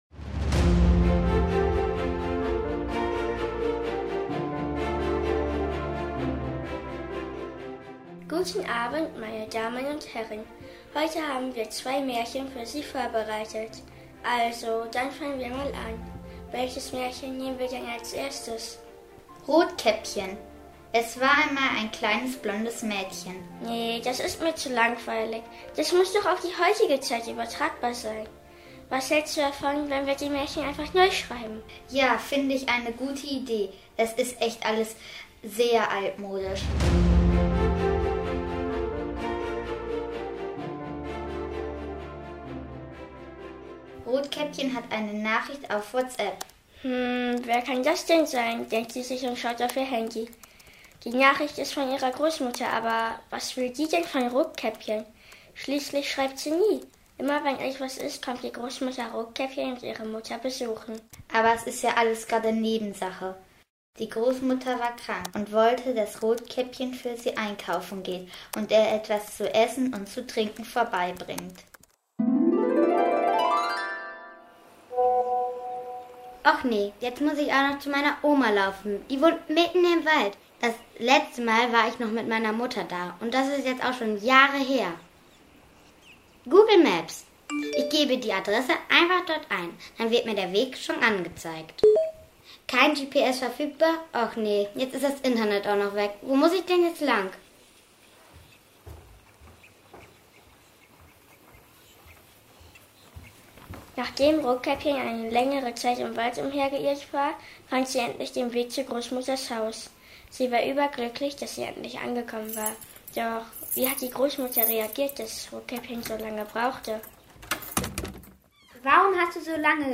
Schüttorfer Theater-AG produziert Hörspiele
01-Rotkäppchen-und-die-falsche-Polizistin-Moderne-Märchen-Komplex-Theater-AG.mp3